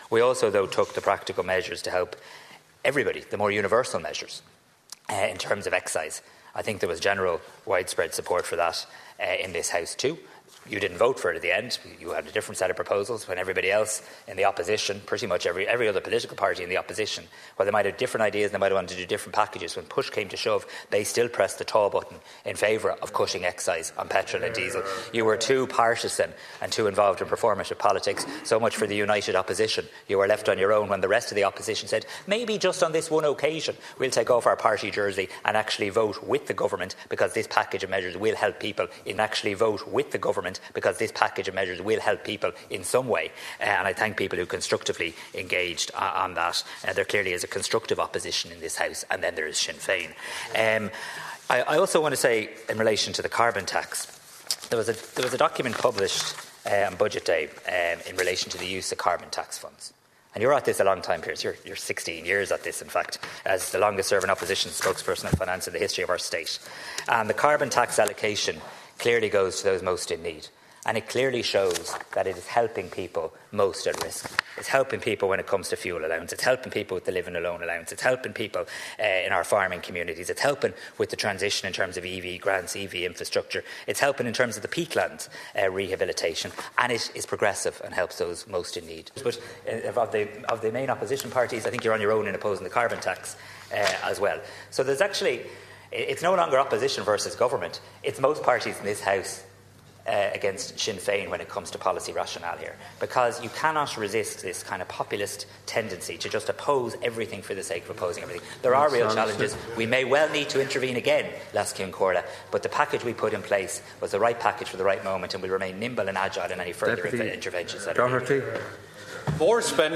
The Government and opposition have clashed over energy prices on the last day of the Dáil before the Easter recess.